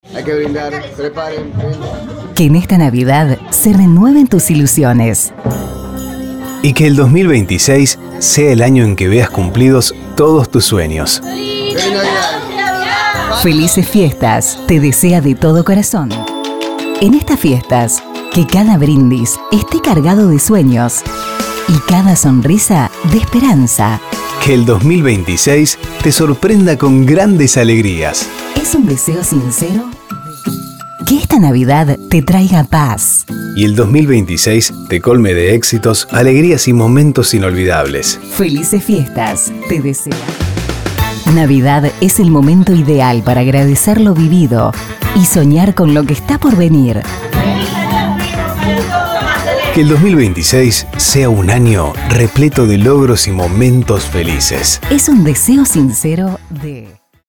Fiestas Pack 5 'Dos Voces'
✅ Fondos musicales y FX de máxima calidad.